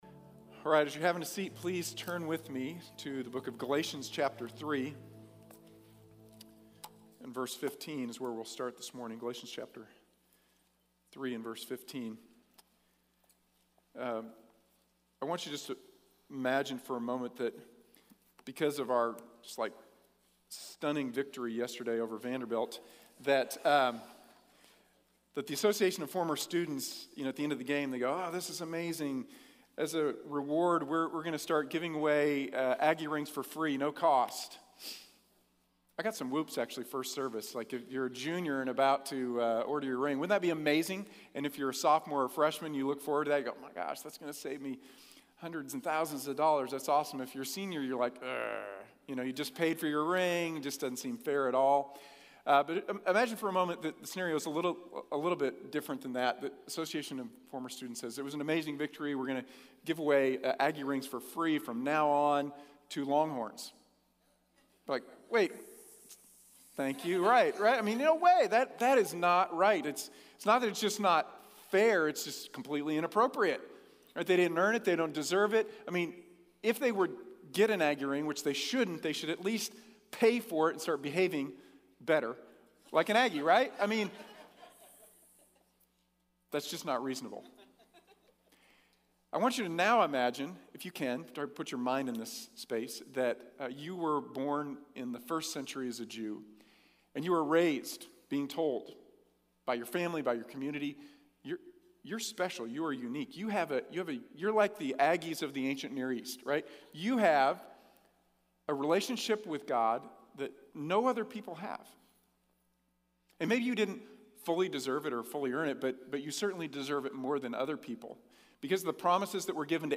Promise Keeping God | Sermon | Grace Bible Church